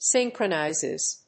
/ˈsɪŋkrʌˌnaɪzɪz(米国英語)/